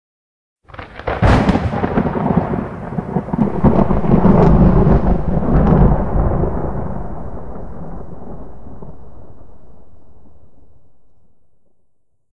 thunder-1.ogg